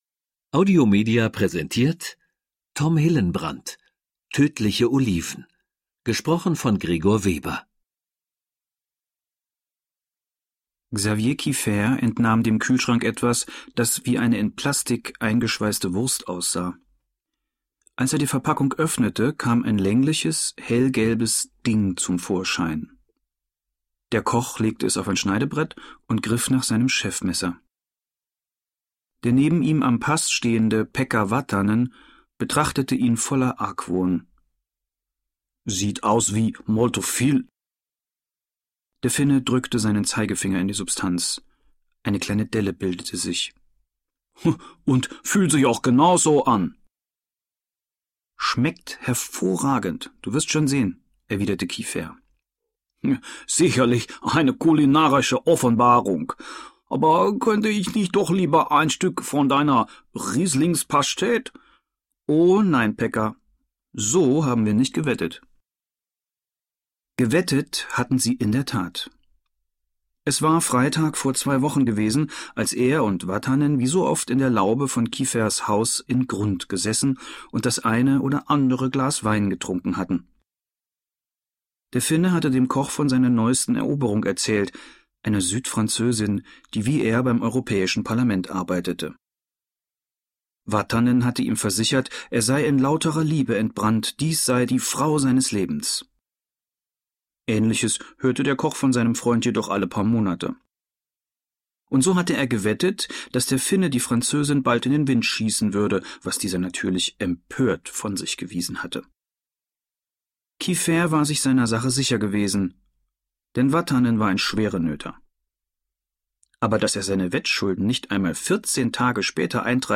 Gregor Weber (Sprecher)
Schlagworte Gourmet • Hörbuch; Krimis/Thriller-Lesung • Italien • Krimi • Kulinarischer Krimi • Toskana • Xavier Kieffer